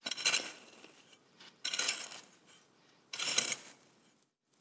Babushka / audio / sfx / Farming / SFX_Harke_01_Reverb.wav
SFX_Harke_01_Reverb.wav